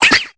Cri de Sepiatop dans Pokémon Épée et Bouclier.
Cri_0686_EB.ogg